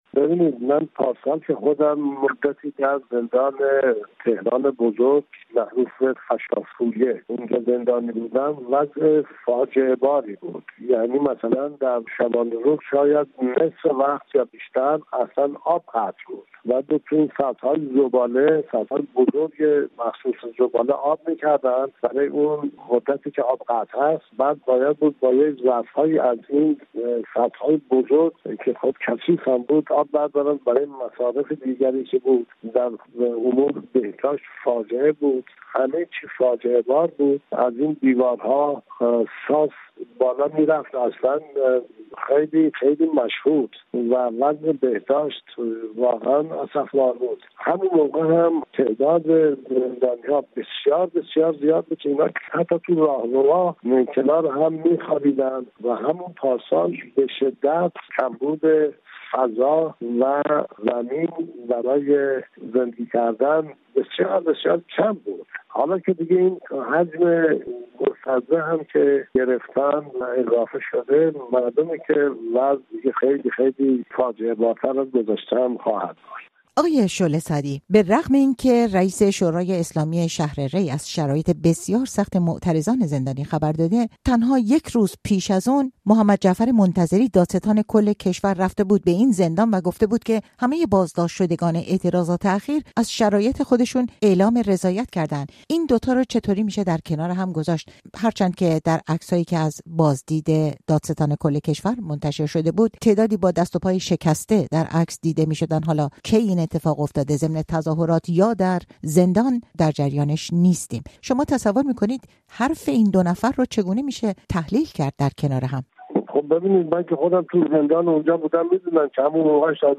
وی در مصاحبه با رادیو فردا گفت که آب زندان در نیمی از شبانه‌روز قطع بود و در سطل‌های بزرگ زباله که کثیف هم بود آب برای مصرف زندانیان ذخیره می‌شد.